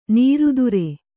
♪ nīrudure